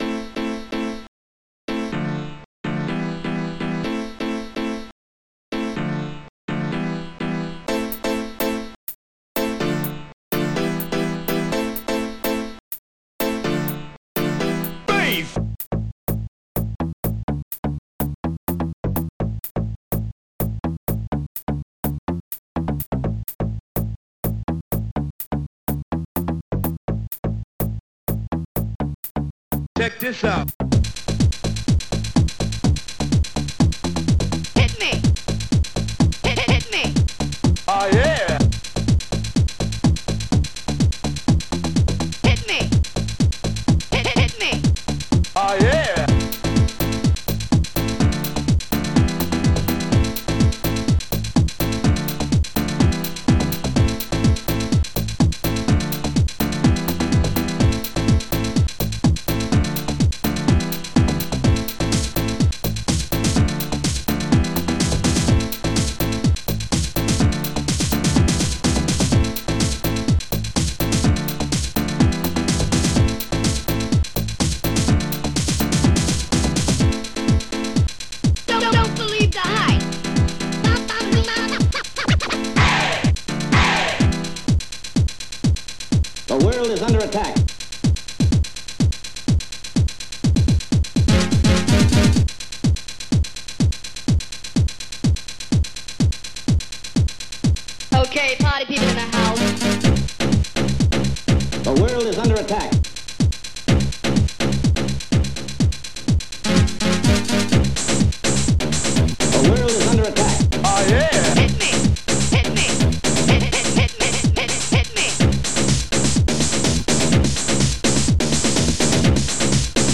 ST-99:housesnare6.sam
ST-99:b.megabass
ST-99:mcs-KLAVIER
ST-99:pumpsynth
ST-99:housescratch1
ST-99:housesynth2